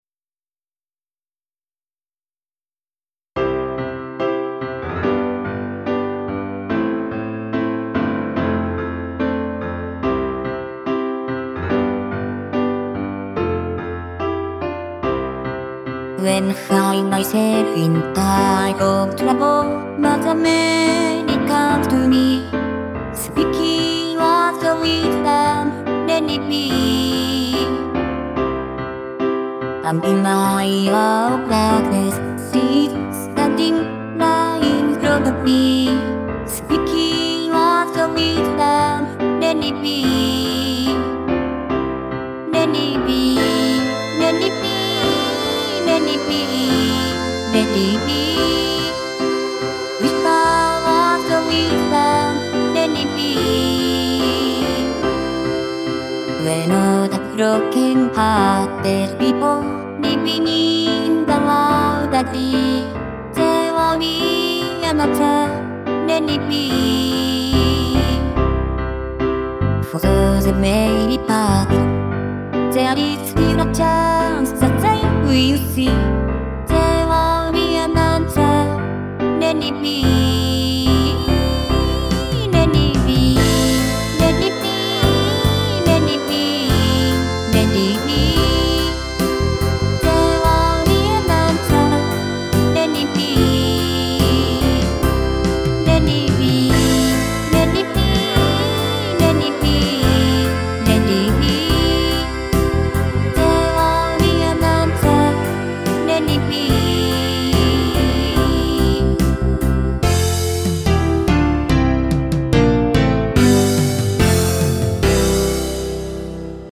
で、今回はOPEを下げることで発音をあいまいにしてごまかしてます。それでも英語には聞こえないわな。